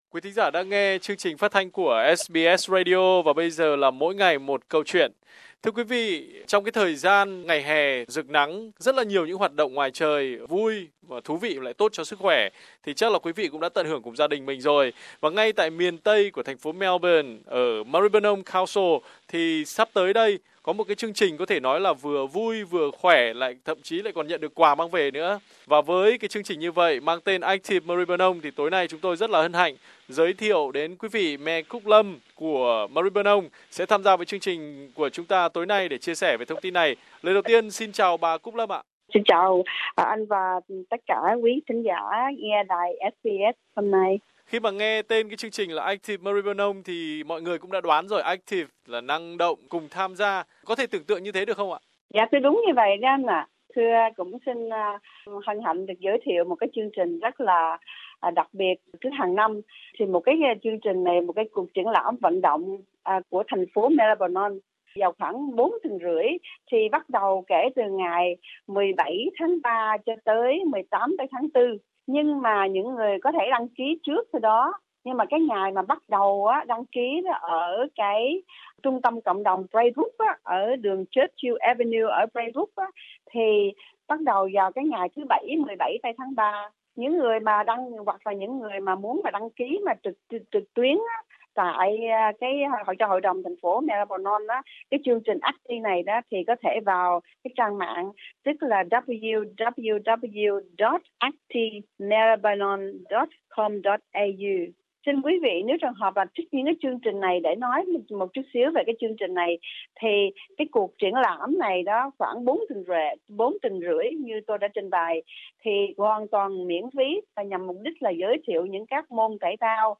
Trước sự kiện đa văn hóa đầy năng động Active Program, bà Thị trưởng Cúc Lâm trong cuộc phỏng vấn với SBS đã nêu bật hiệu quả của các chương trình diễn ra thường niên từ năm 2014.